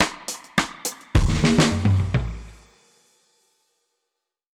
Index of /musicradar/dub-drums-samples/105bpm
Db_DrumsB_Wet_105-04.wav